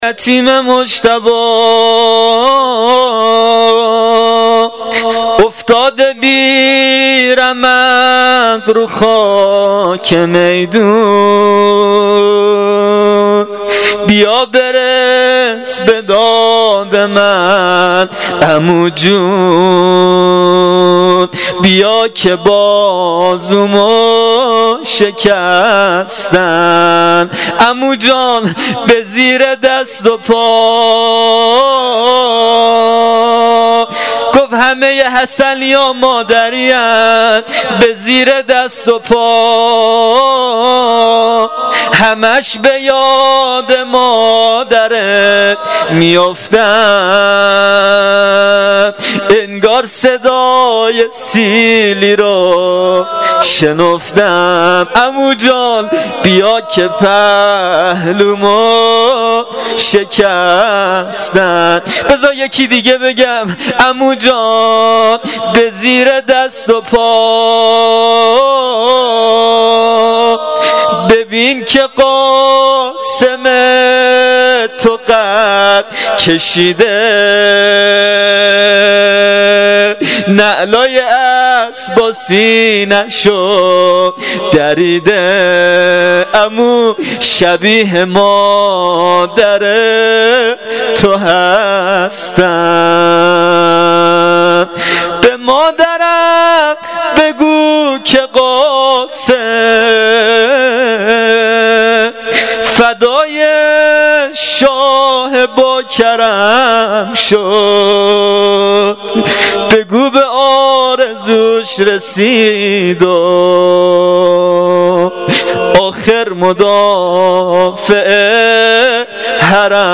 زمزمه شب ششم محرم الحرام 1396